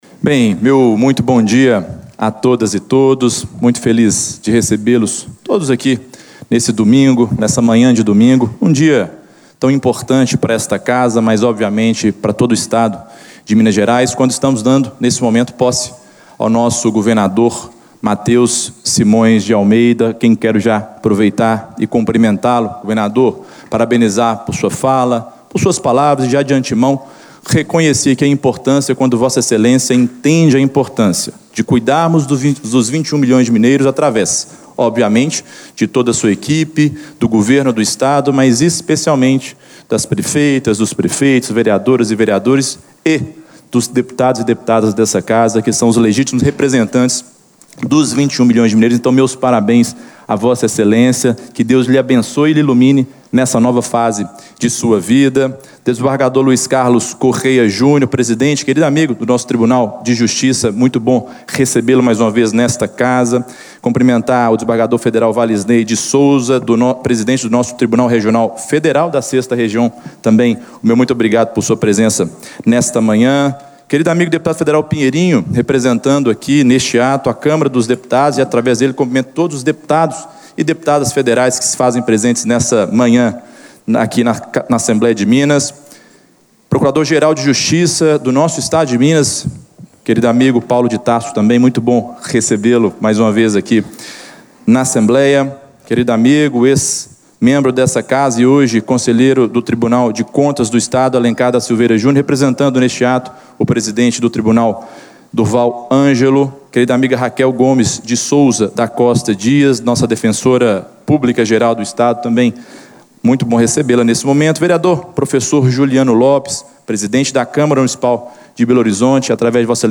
Discursos e Palestras
Durante reunião solene no Plenário, para dar posse ao novo governador mineiro, o Presidente da Assembleia Legislativa destacou que o papel do poder legislativo é continuar atento aos debates que interessam Minas respeitando a autonomia dos mandatos.